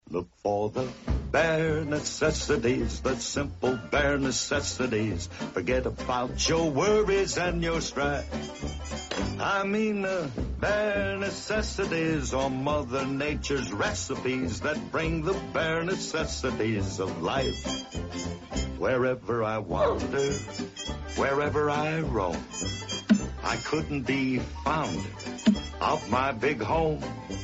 Filmmusik